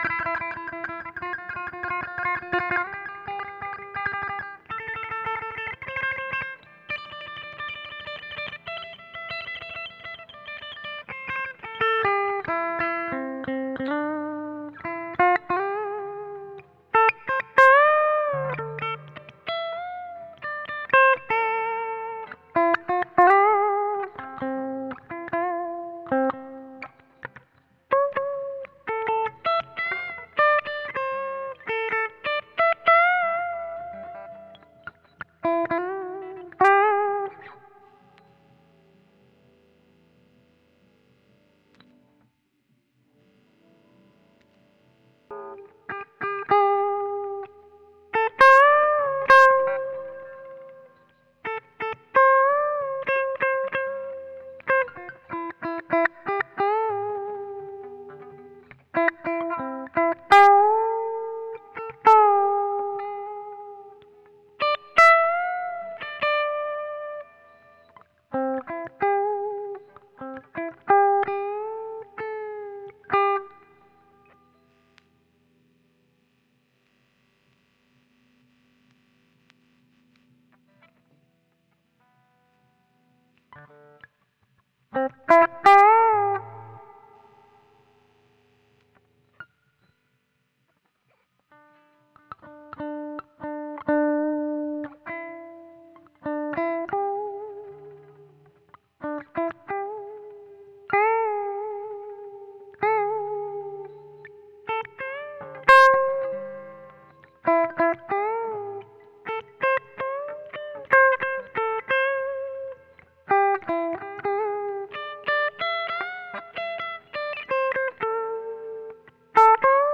funky gratte 01.aif